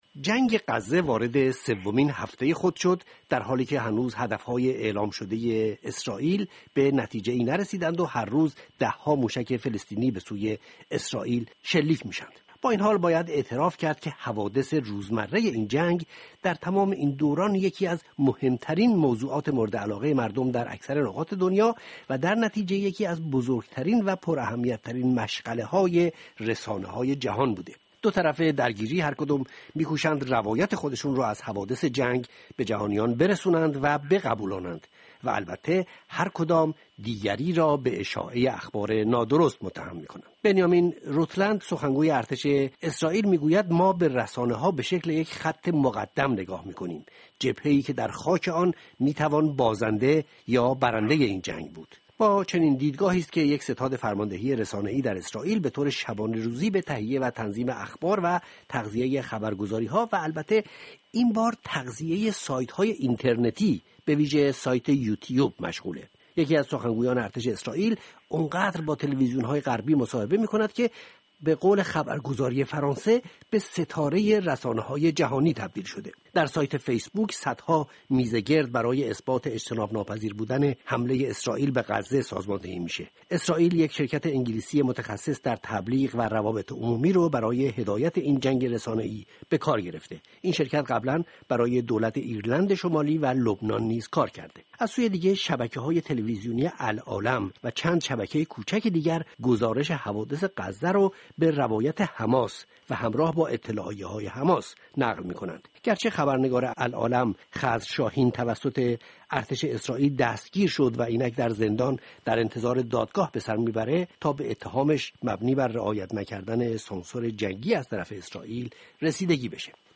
میزگرد رادیوئی را بشنوید